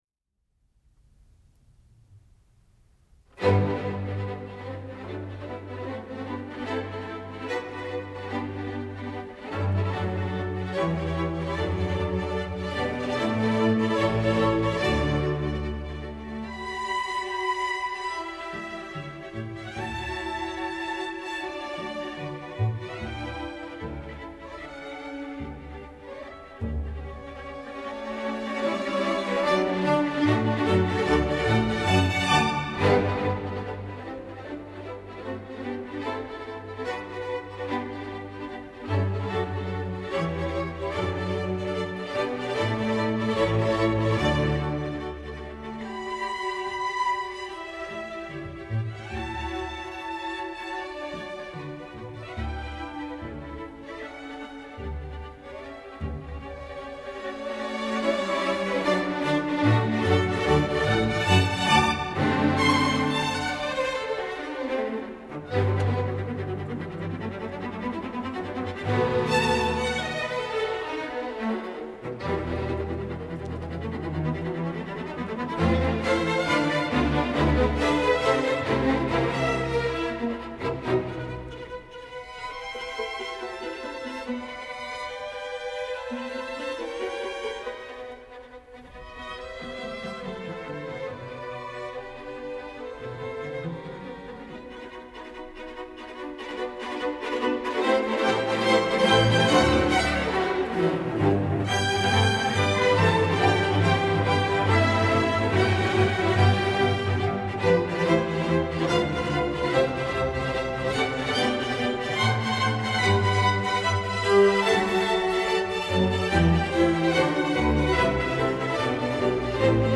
Prelude to “Holberg Suite” by The Academy of St. Martin-in-the-Fields (Classical)